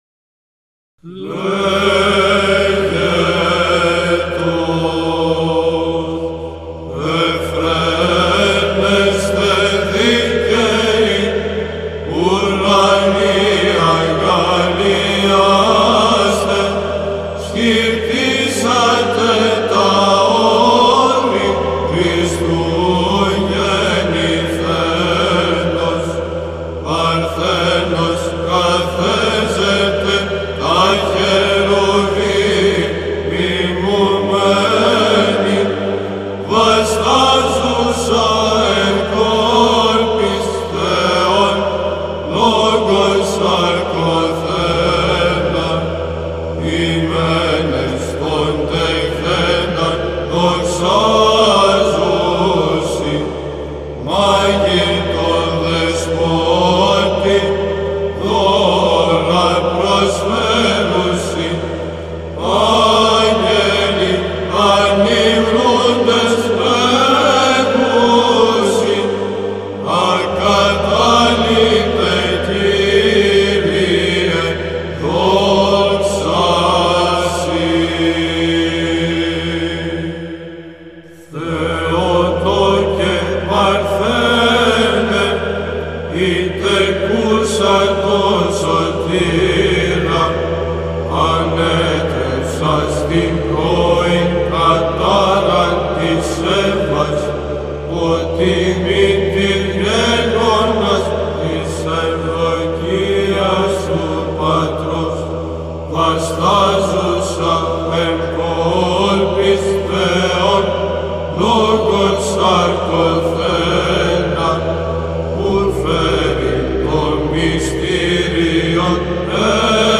ΒΥΖΑΝΤΙΝΟΙ ΥΜΝΟΙ ΧΡΙΣΤΟΥΓΕΝΝΩΝ
στιχηρά ιδιόμελα των αίνων, ήχος δ΄ λέγετος